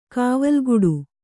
♪ kāvalguḍu